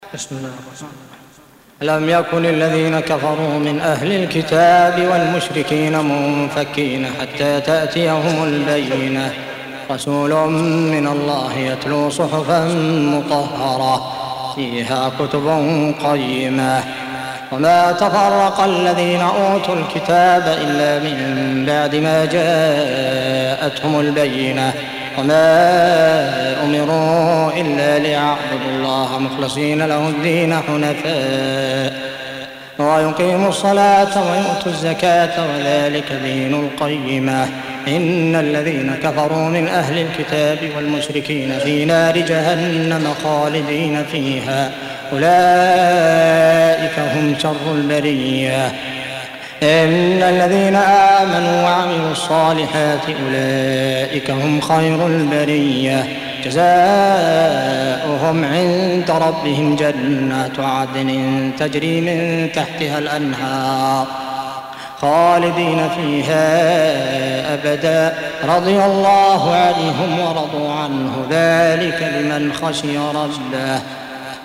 Audio Quran Tarteel Recitation
Surah Sequence تتابع السورة Download Surah حمّل السورة Reciting Murattalah Audio for 98. Surah Al-Baiyinah سورة البينة N.B *Surah Includes Al-Basmalah Reciters Sequents تتابع التلاوات Reciters Repeats تكرار التلاوات